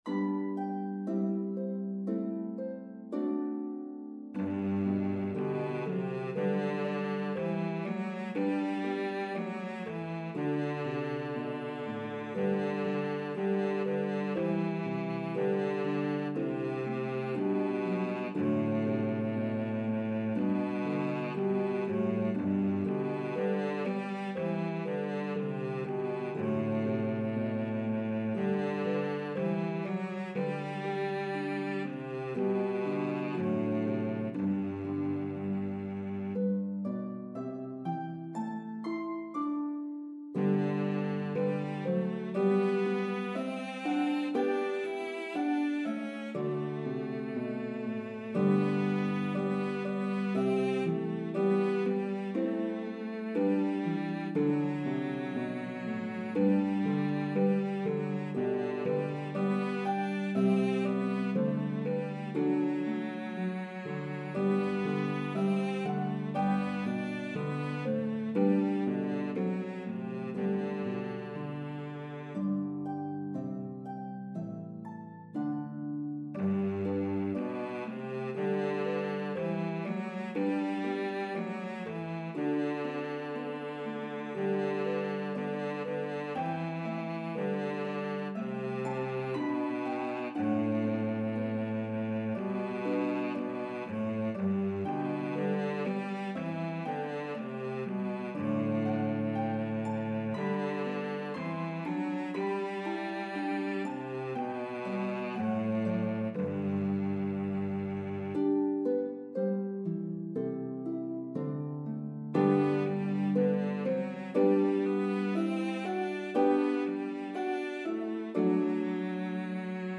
Playable on Lever or Pedal Harps.